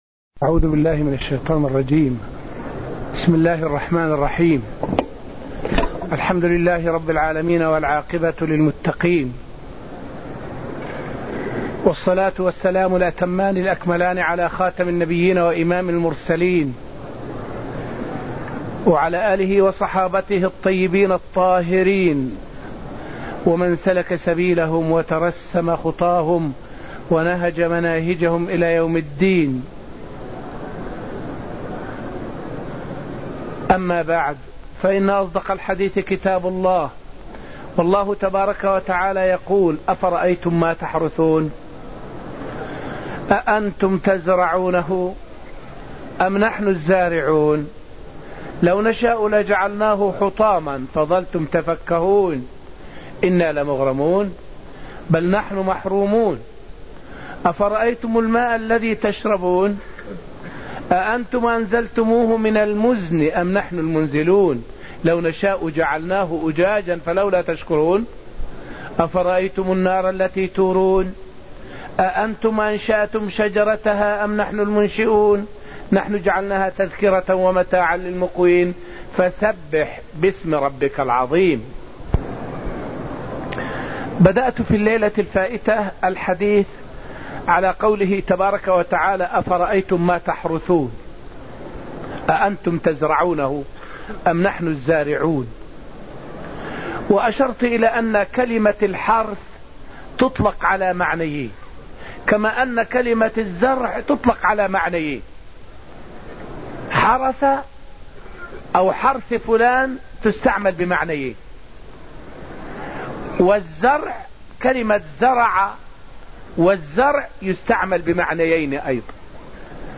الدرس التاسع من دروس تفسير سورة الواقعة من دروس المسجد النبوي تفسير الآيات من قوله تعالى {أفبهذا الحديث أنتم مدهنون} الآية 81 إلى سورة الحديد قوله تعالى {يولج الليل في النهار} الآية 6.